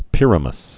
(pĭrə-məs)